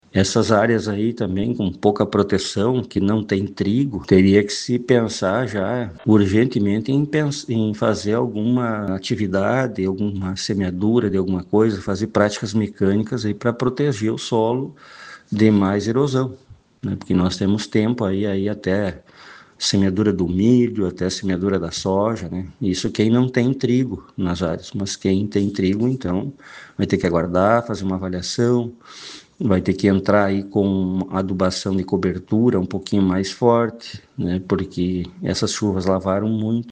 Abaixo, sonora